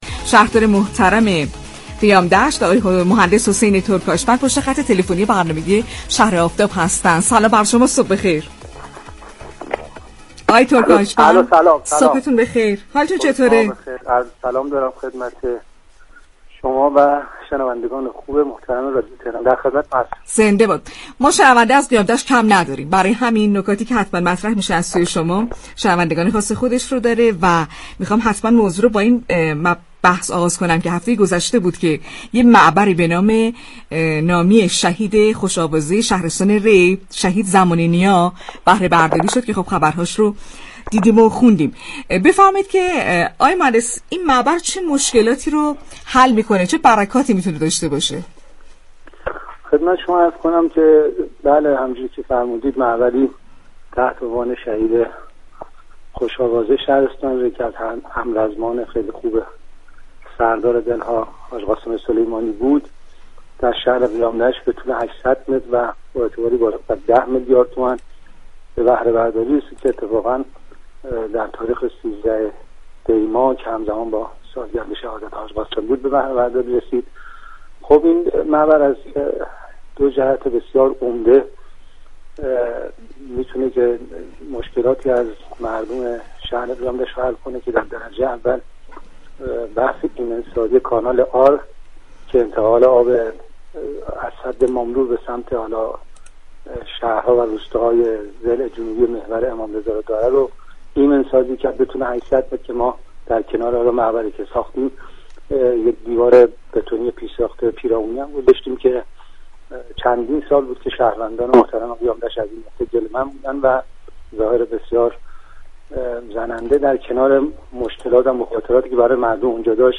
به گزارش پایگاه اطلاع رسانی رادیو تهران، حسین تركاشوند شهردار شهر قیام‌دشت در گفت و گو با «شهر آفتاب» اظهار داشت: بلوار شهید وحید زمانی‌نیا شهید همرزم سردار شهید قاسم سلیمانی در شهرری به منظور كاهش بار ترافیكی منطقه به بهره‌برداری رسید.